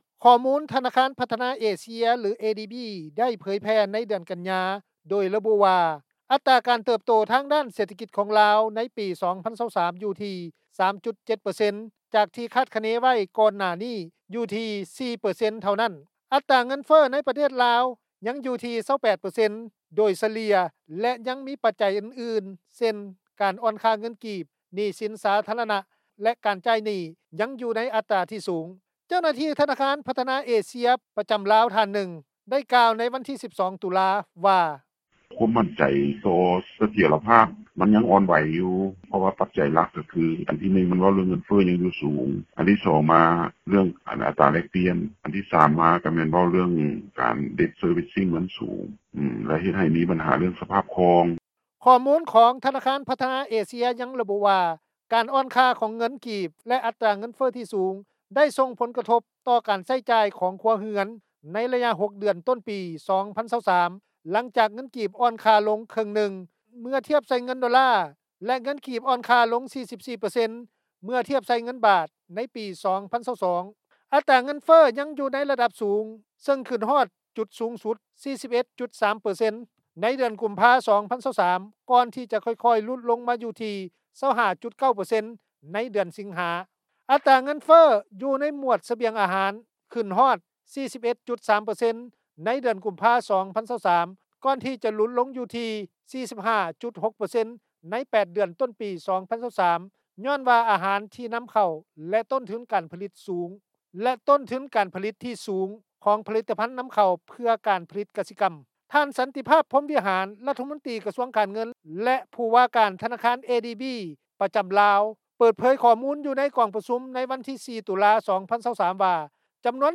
ເຈົ້າໜ້າທີ່ ທະນາຄານພັທນາເອເຊັຽ ປະຈໍາລາວ ທ່ານນຶ່ງ ໄດ້ກ່າວຕໍ່ວິທຍຸ ເອເຊັຽເສຣີ ໃນວັນທີ 12 ຕຸລາ ວ່າ:
ຊາວລາວ ທ່ານນຶ່ງ ໄດ້ກ່າວຕໍ່ວິທຍຸ ເອເຊັຽເສຣີ ວ່າ: